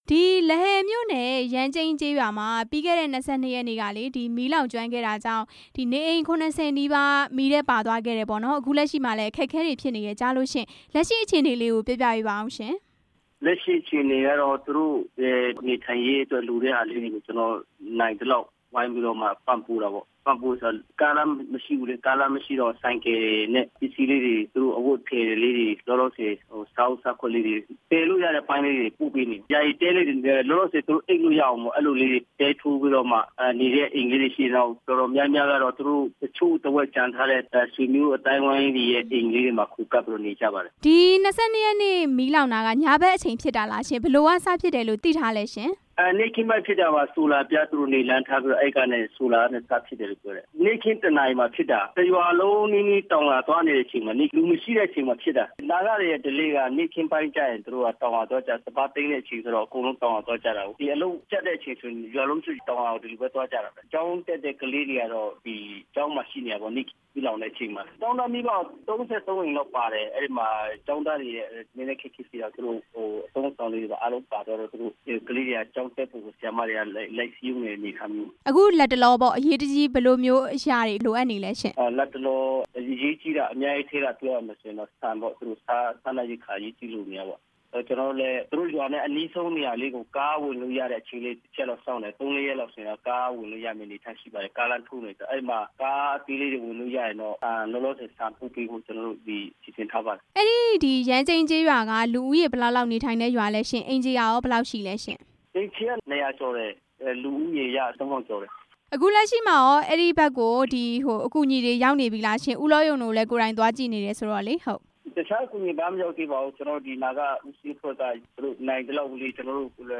နာဂကိုယ်ပိုင်အုပ်ချုပ်ခွင့်ရဒေသ လဟယ်မြို့နယ်၊ ယမ်းကြိမ်းကျေးရွာမှာ ပြီးခဲ့တဲ့ နိုဝင်ဘာလ ၂၂ ရက်နေ့က မီးလောင်မှုကြောင့် အိုးမဲ့အိမ်မဲ့ဖြစ်နေတဲ့ လူဦးရေ ၅၀၀ နီးပါးအတွက် စားနပ်ရိက္ခာတွေ လိုအပ်နေတယ်လို့ လဟယ်မြို့နယ် တိုင်းဒေသကြီး လွှတ်တော်ကိုယ်စားလှယ် ဦးလော်ယုံ က ပြောပါတယ်။